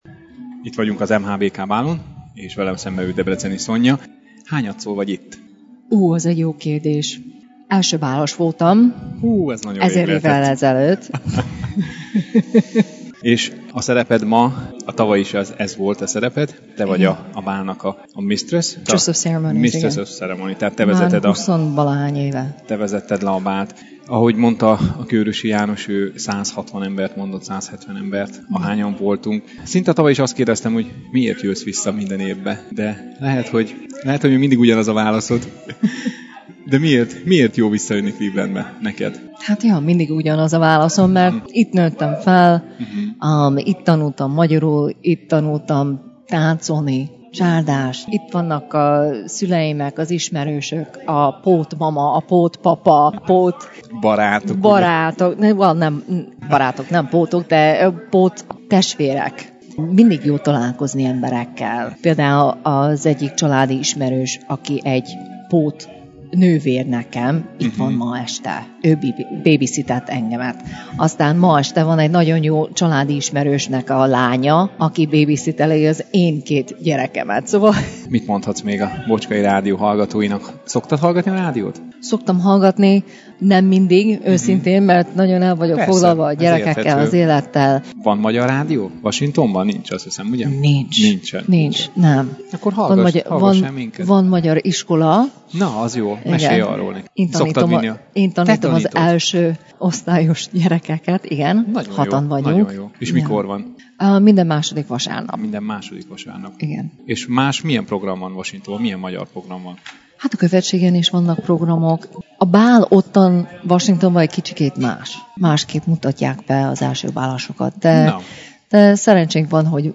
Beszámoló a 2014-es clevelandi MHBK bálról – Bocskai Rádió